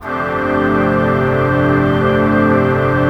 21PAD 01  -L.wav